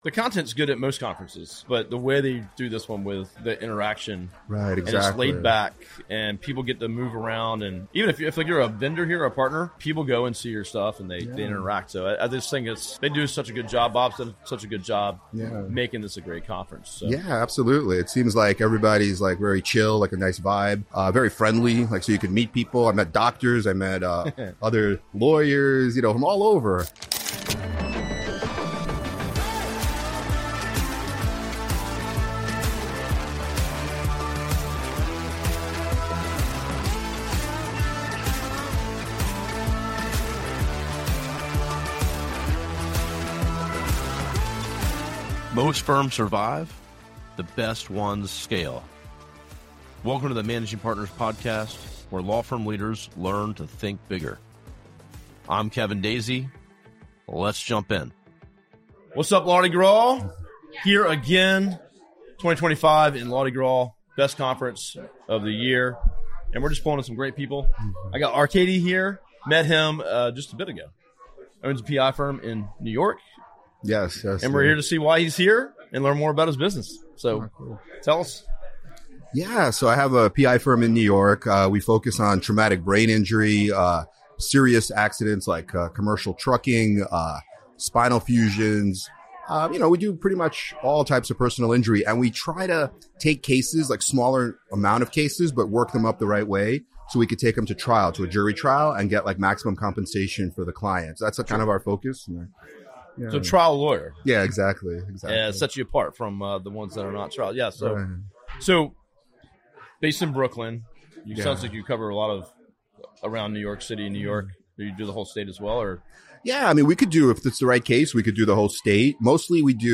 • (00:06:49) - Lardi Gras 2017 conference interview